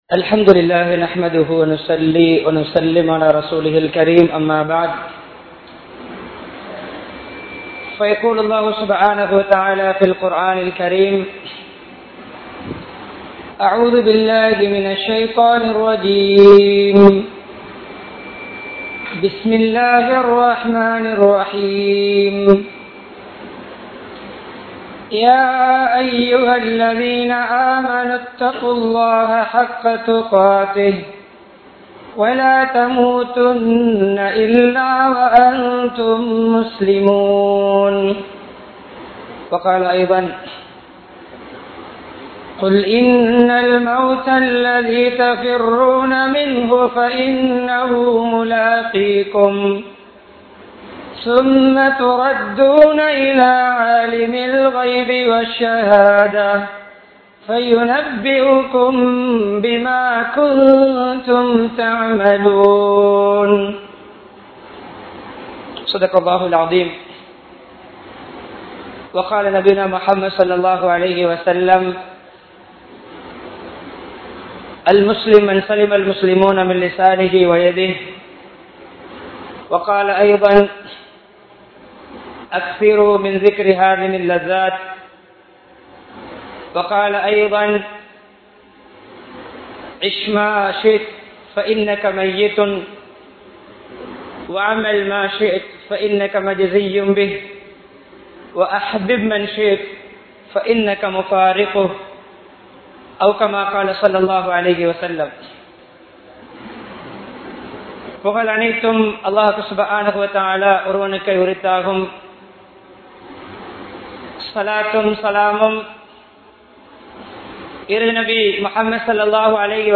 Maranaththai Ninaiththu Valvai Maatruvoam (மரணத்தை நினைத்து வாழ்வை மாற்றுவோம்) | Audio Bayans | All Ceylon Muslim Youth Community | Addalaichenai
Mutwal Jumua Masjidh